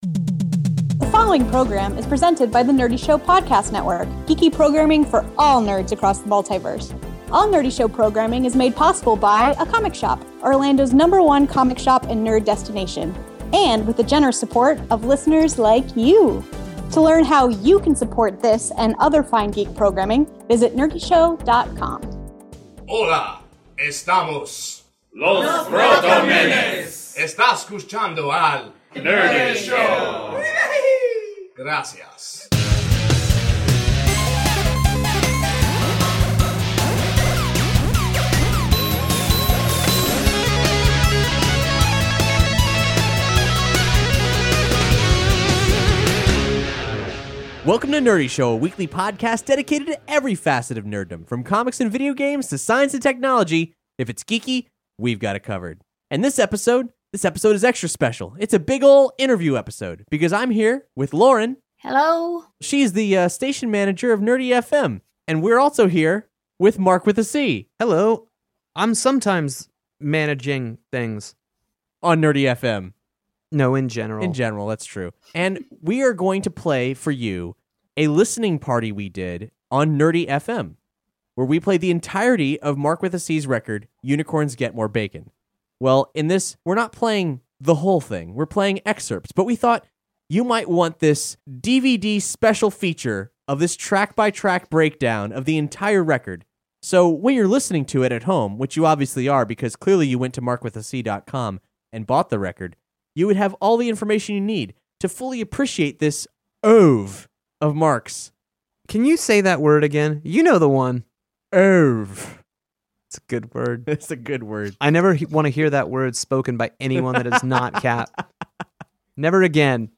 Nerdy Show 253 :: Interview